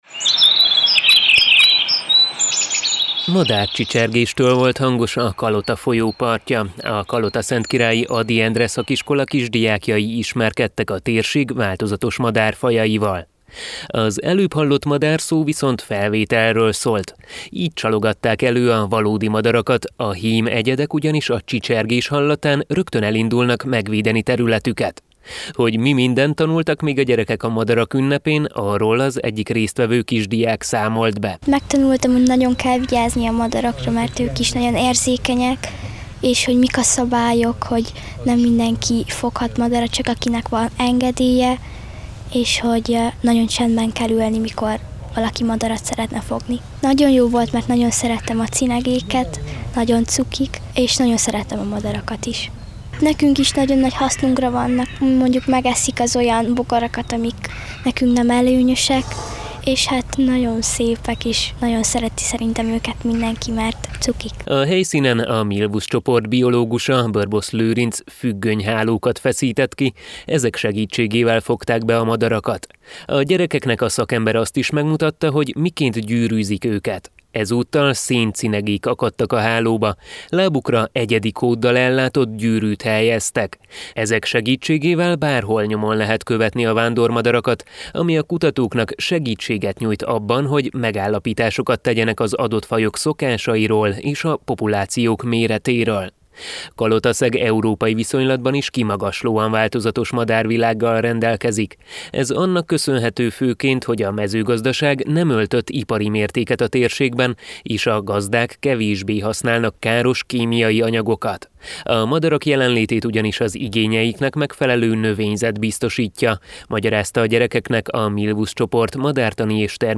Kalotaszentkirályon három madárinformációs táblát avattak fel, ezeken a térség madárfajait gyűjtötték össze. A településen mintegy 120 kisdiák vonult fel a madarakat ünnepelve: zenével, énekkel és jókedvvel hívták fel a figyelmet a helyi madárfajok és az élővilág védelmének fontosságára.